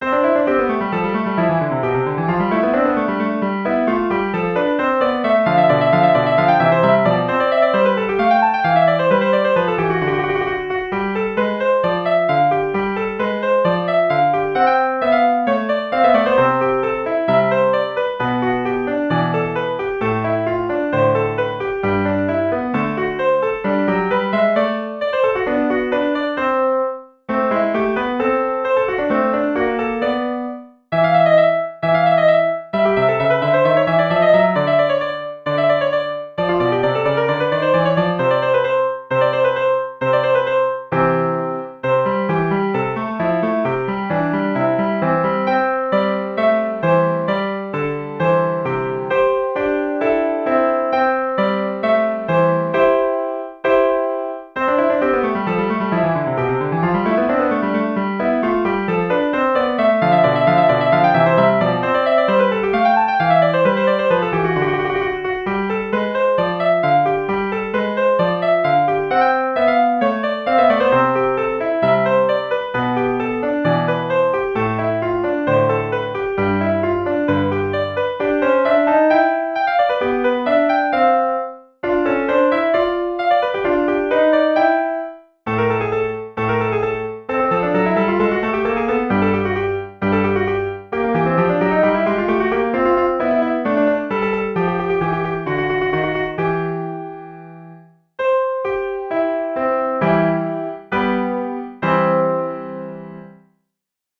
ピアノ練習曲（暫定稿・ファイル容量大：3,307kB
この曲はハ長調のソナタ形式で作られています。
右手のトリルで第一主題が締めくくられます。
第一主題をモチーフとして、ハ長調の第一主題と分散和音で始まります。
後半はペダルを使て演奏することを前提としています。
アルペシオと分散和音で構成され、最後はハ長調の第一和音で終わります。